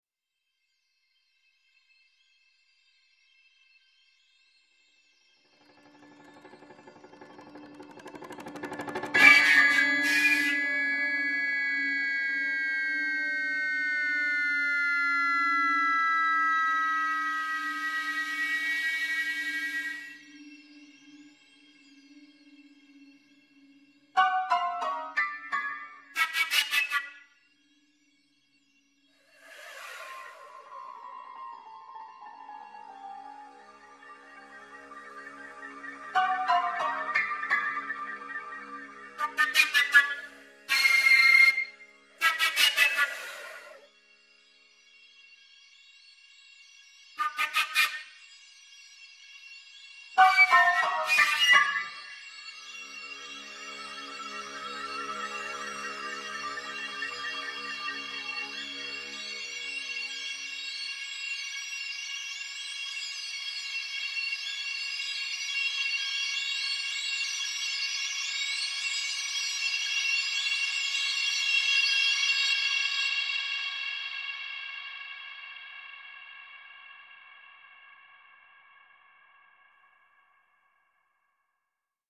für Flöte, Klavier und 6-Kanal-Zuspielung
Ausschnitte Mono